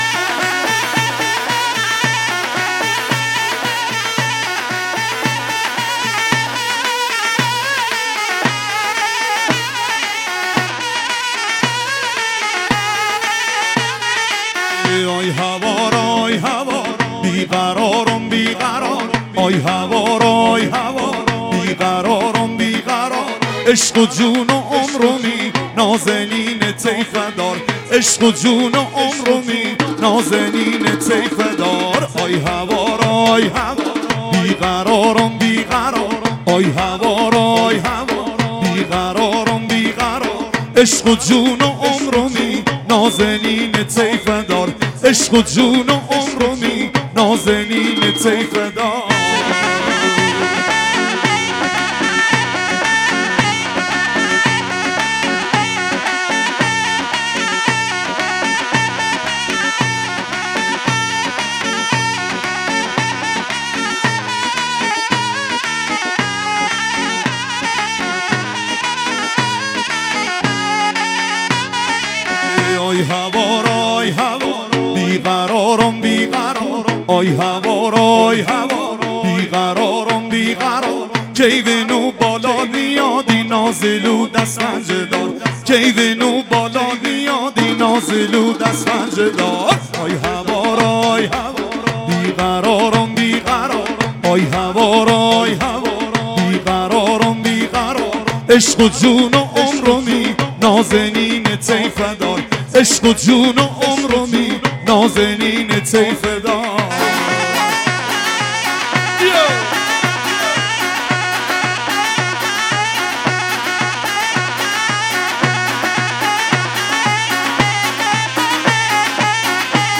محلی لری عروسی